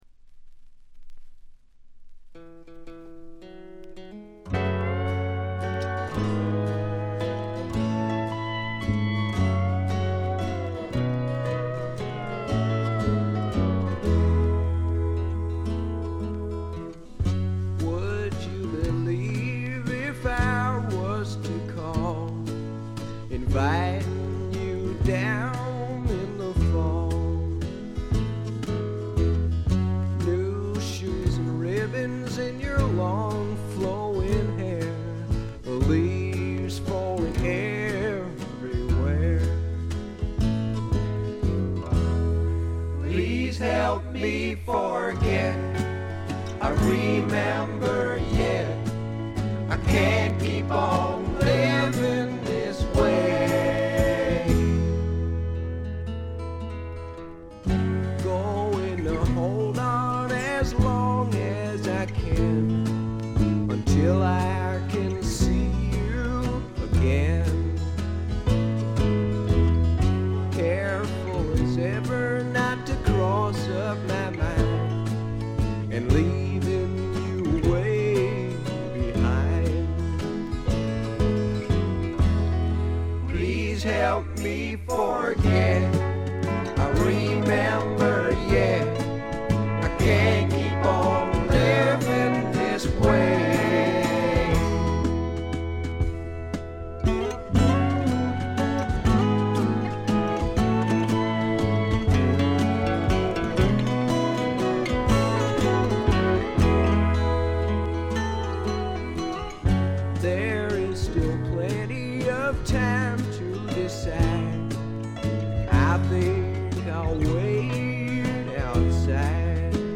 軽微なバックグラウンドノイズにチリプチ少々。
試聴曲は現品からの取り込み音源です。